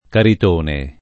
[ karit 1 ne ]